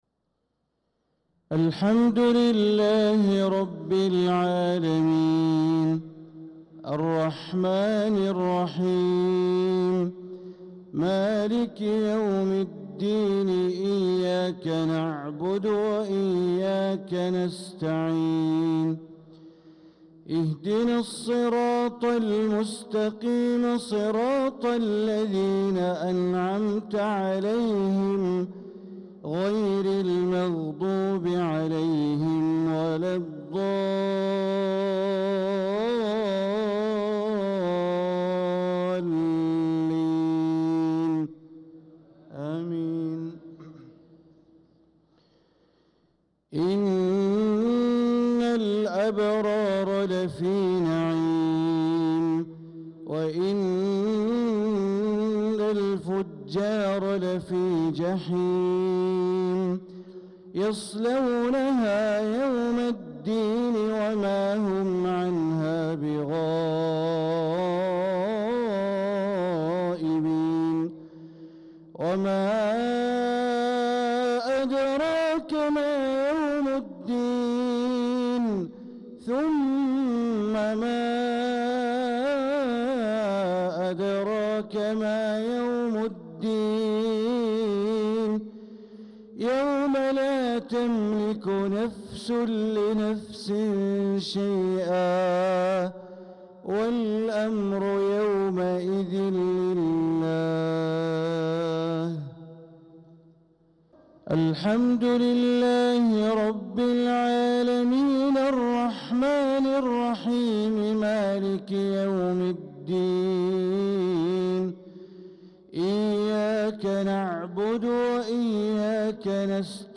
صلاة العشاء للقارئ بندر بليلة 11 ذو الحجة 1445 هـ
تِلَاوَات الْحَرَمَيْن .